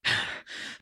breath2.ogg